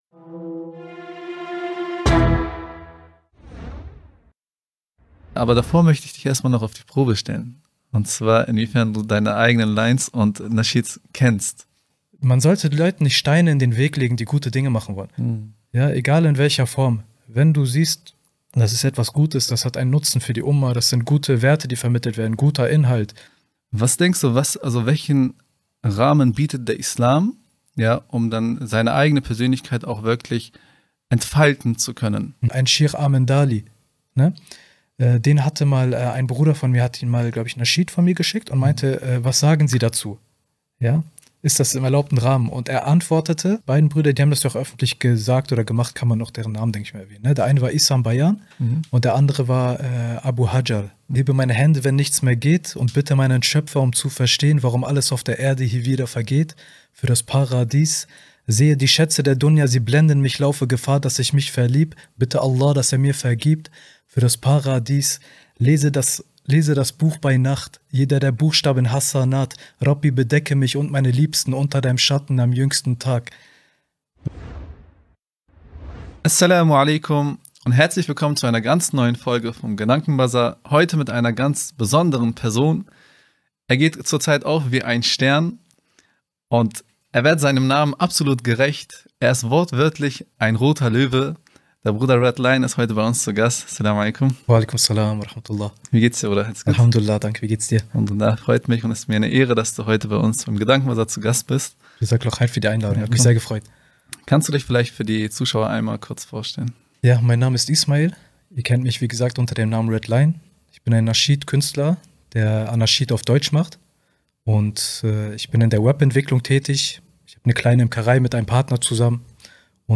haben eine spannendes Gespräch geführt